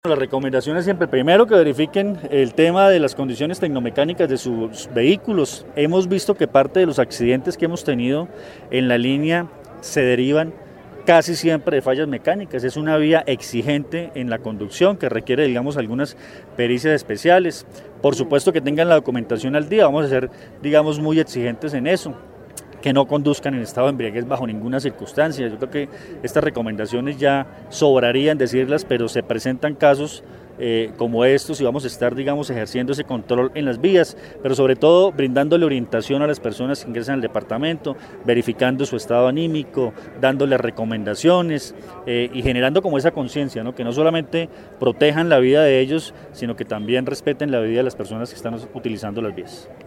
Comandante de la Policía del Quindío, Luis Fernando Atuesta Zarate